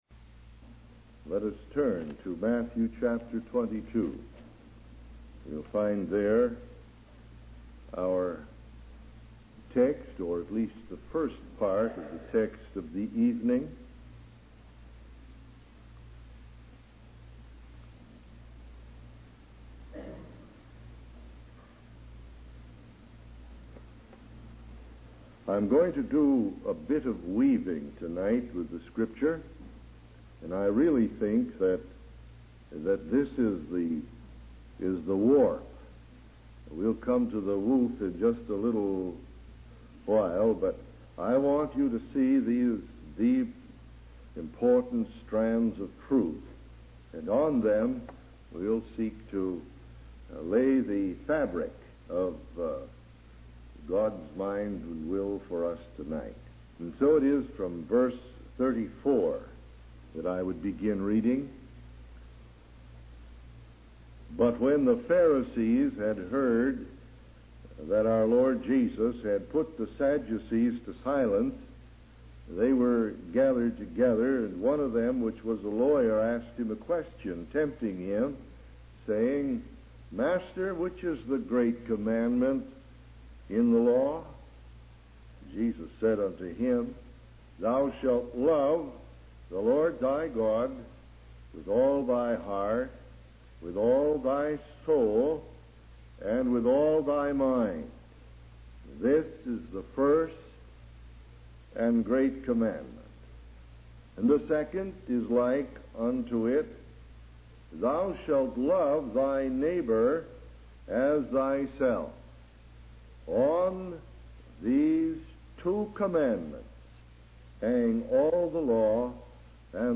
In this sermon, the speaker discusses the importance of loving and serving others as a reflection of our love for God. He emphasizes that individuals make up nations, and therefore, our actions towards others have a significant impact.